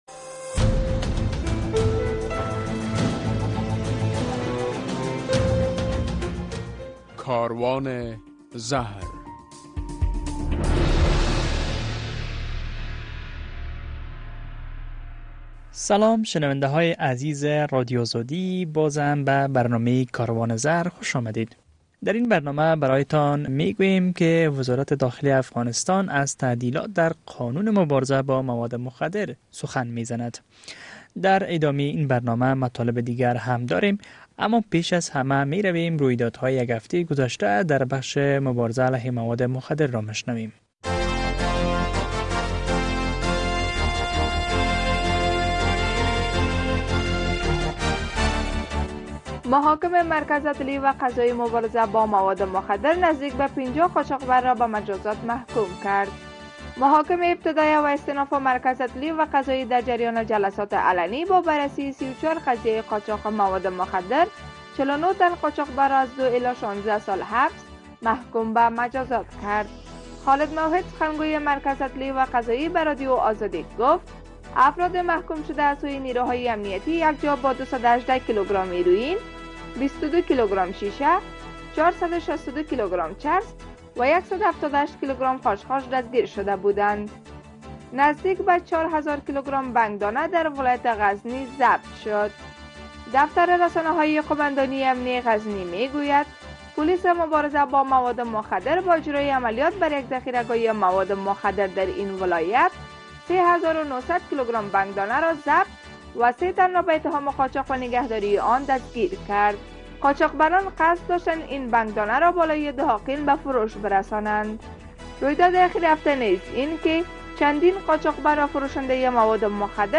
در برنامه این هفته کاروان زهر نگاهی به رویداد های مهم در بخش مواد مخدر، در یک گزارش می شنوید که وزارت داخله از تعدیل آوردن در قانون مبارزه علیه مواد مخدر خبر می‌دهد. در یک گزارش از باشندگان ولایت پروان در ارتباط به فروش و قاچاق مواد مخدر در این ولایت می‌شنوید. مصاحبه در باره نقش رسانه های...